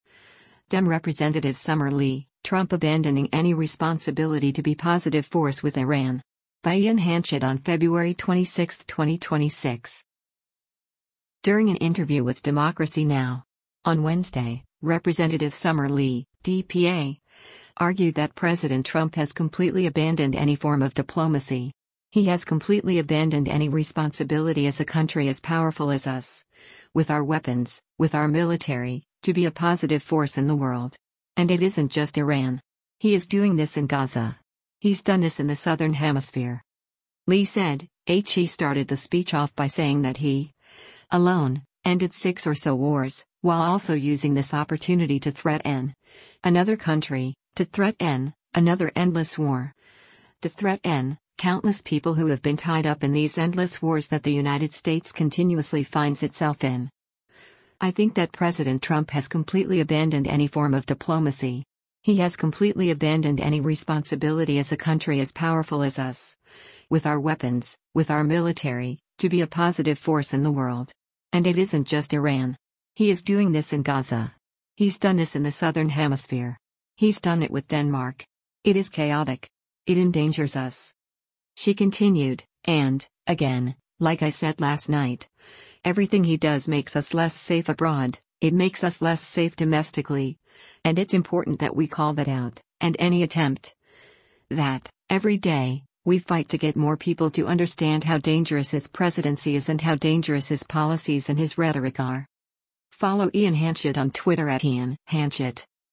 During an interview with “Democracy Now!” on Wednesday, Rep. Summer Lee (D-PA) argued that “President Trump has completely abandoned any form of diplomacy.